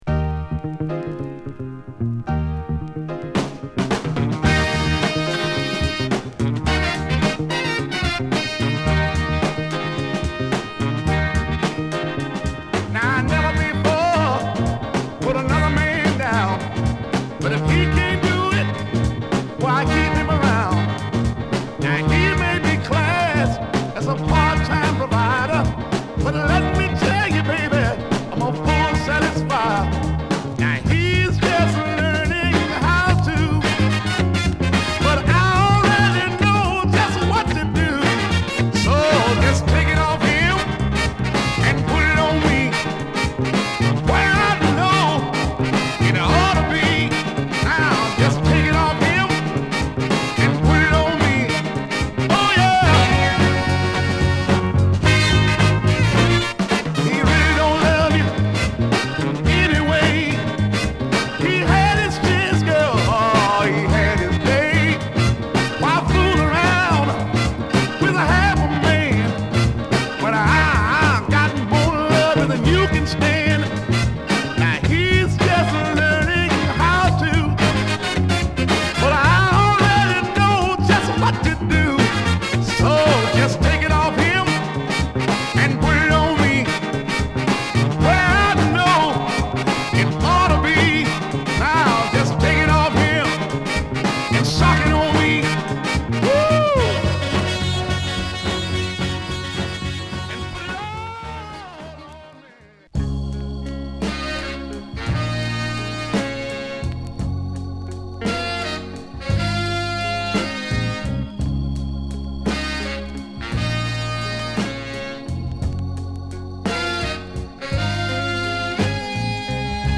Home > Southern Soul
盲目のシンガーソングライター／ギタリスト